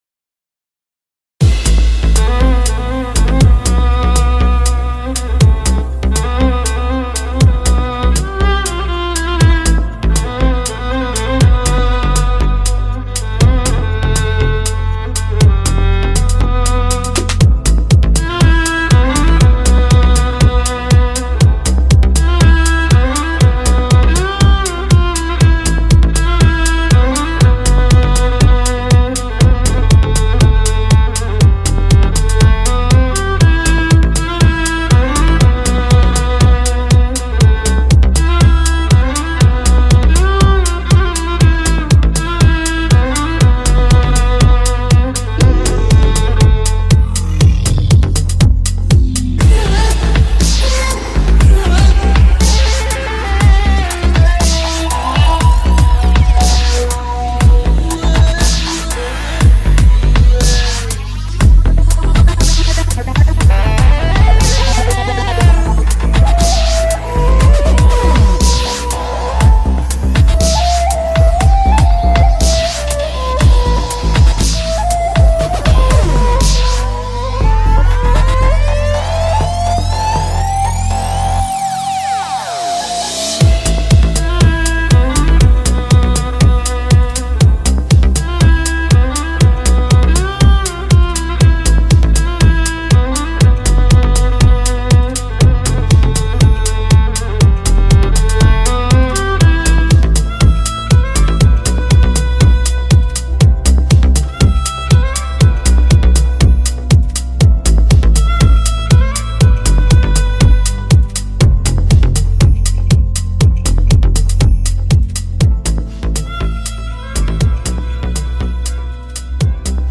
tema dizi müziği, duygusal hüzünlü gerilim fon müzik.